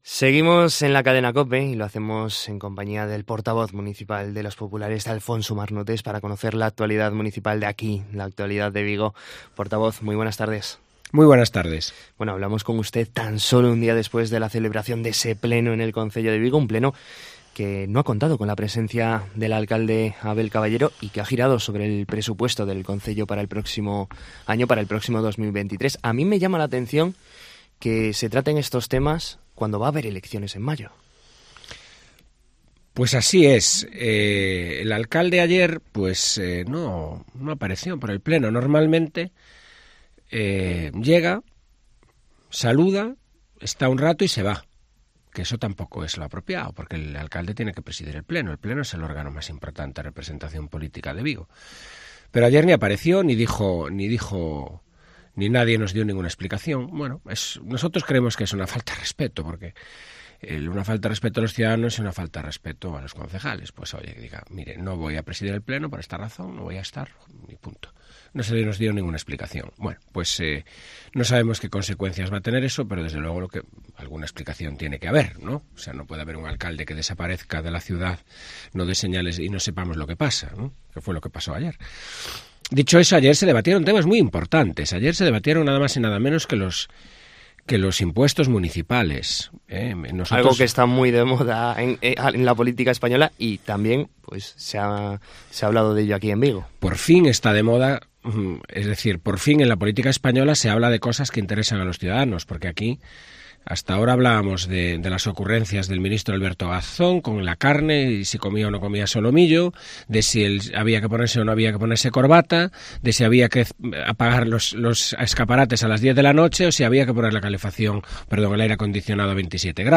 Conocemos la actualidad de Vigo de la mano del portavoz municipal del Partido Popular